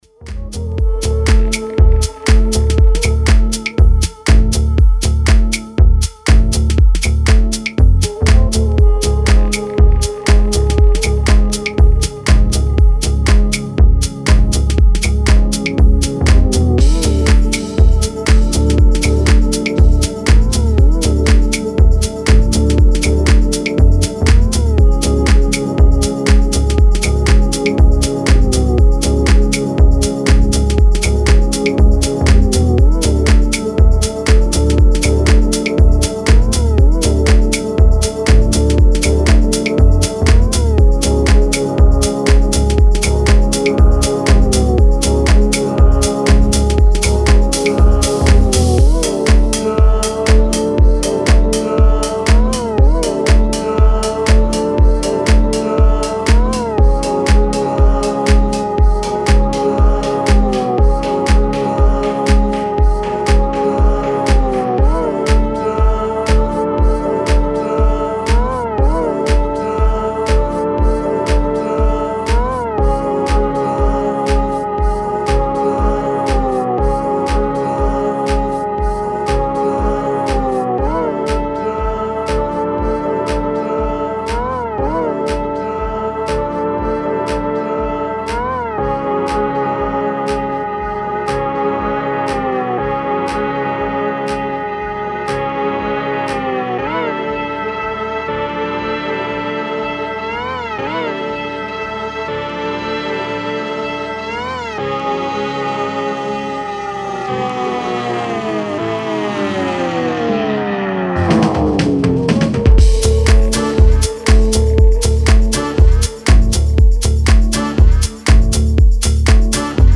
Sensual music for having goosebumps.
Style: Techno / Tech House